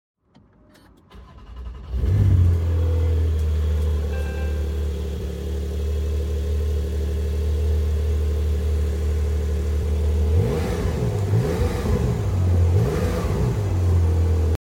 Porsche 911 tagra start up sound effects free download
Porsche 911 tagra start up and revs